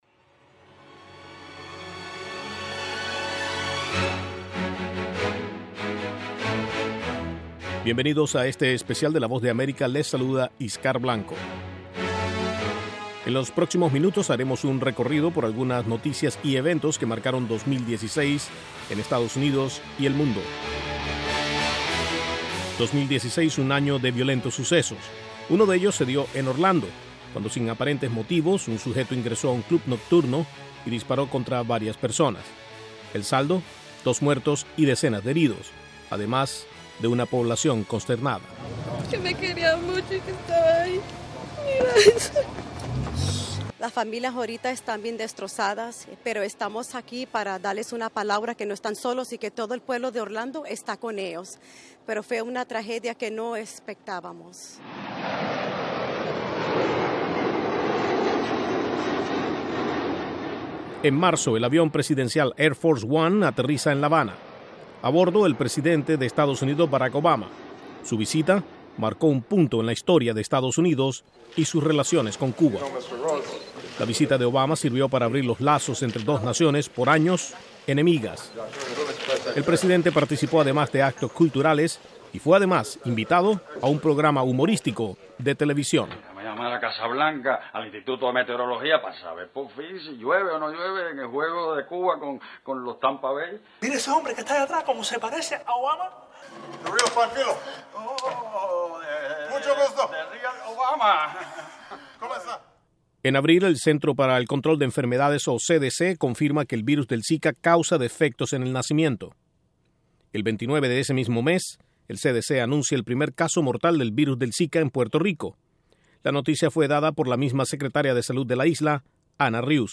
Noticias y eventos que marcaron el año 2016 en un reporte especial de la Voz de América.